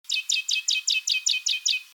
Listen to the singing of three Darwin´s finch species:
a Medium Tree Finch, a
Song_Medium_Tree_Finch.mp3